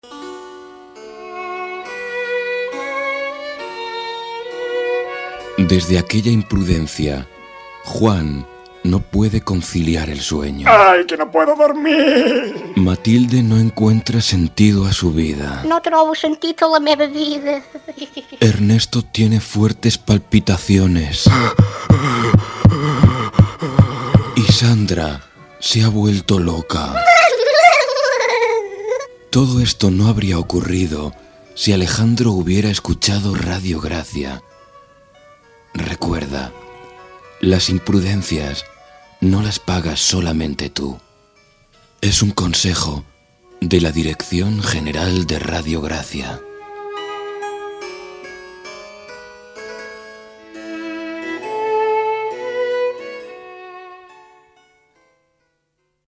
Indicatiu de l'emissora "las imprudencias no las pagas solo tú".